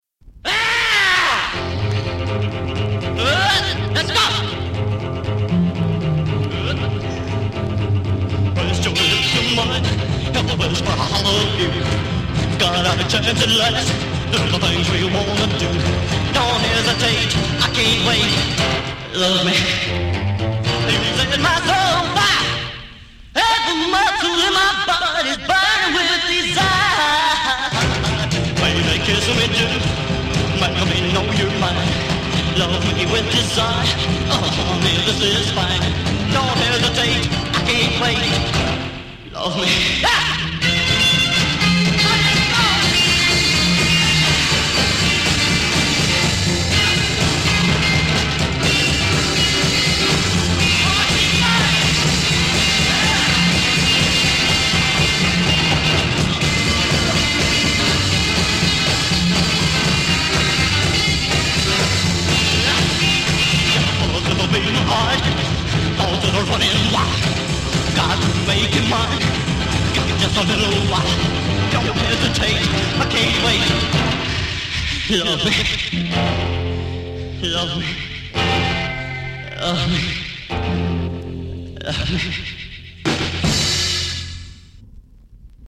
Electric Guitar
Bass Guitar
Drums
Piano